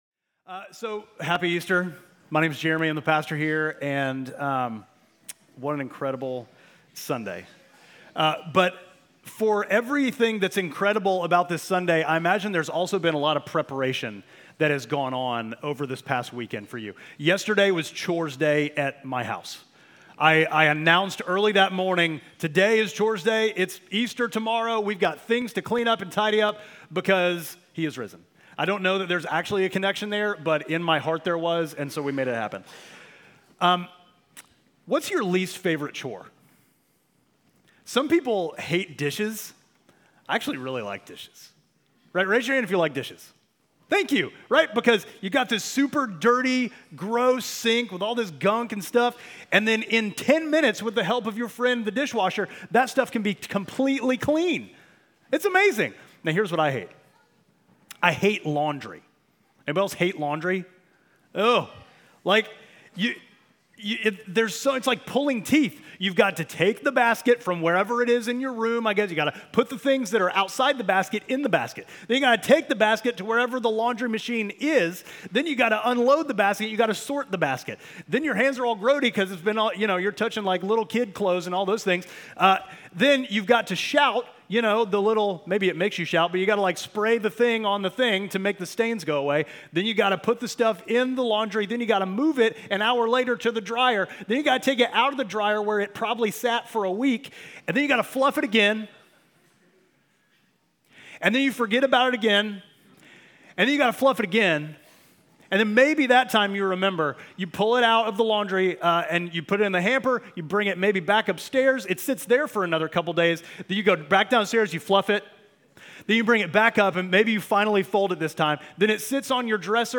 Midtown Fellowship Crieve Hall Sermons Easter Sunday Apr 20 2025 | 00:26:15 1x 00:00 / 00:26:15 Subscribe Share Apple Podcasts Spotify Overcast RSS Feed Share Link Embed